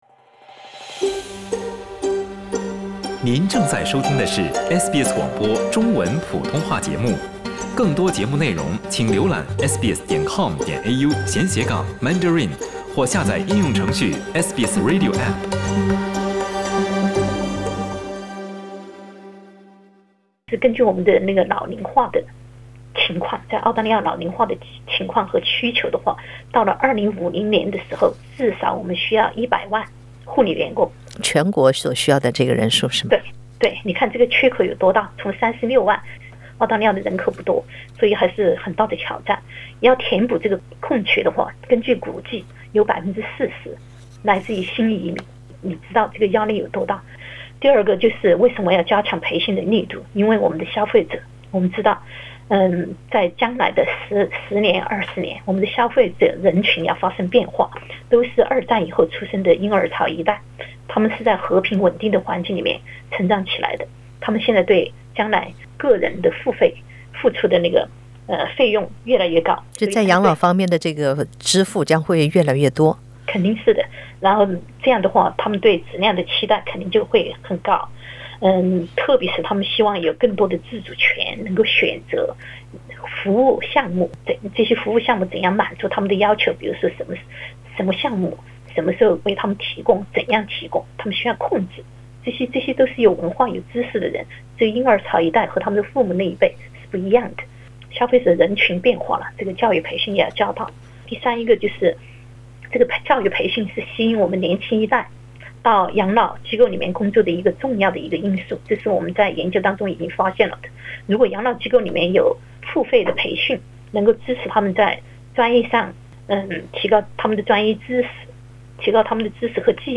专家指出，不久的将来，全澳需要100万养老护理员工，但目前仅有30多万。（点击上图收听报道）